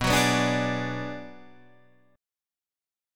Listen to Bm6 strummed